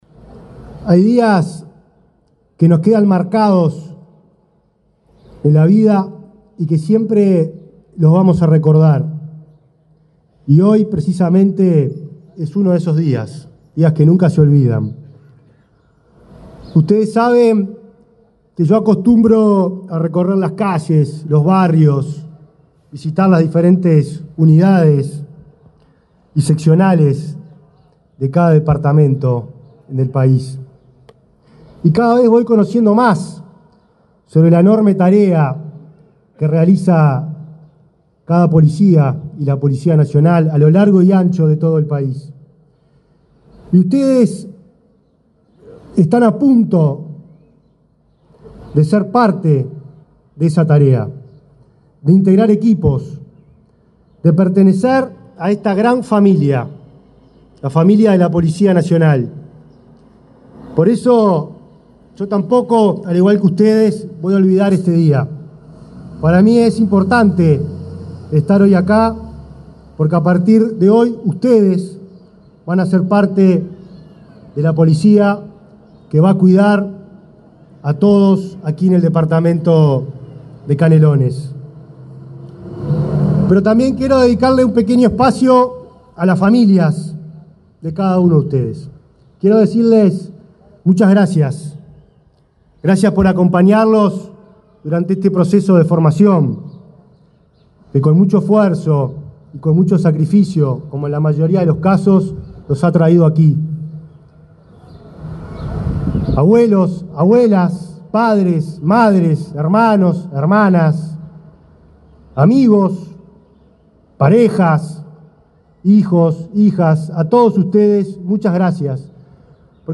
Palabras del ministro del Interior, Nicolás Martinelli
Este martes 10, el ministro del Interior, Nicolás Martinelli, asistió a la ceremonia de egreso de alumnos de la quinta promoción de la Escuela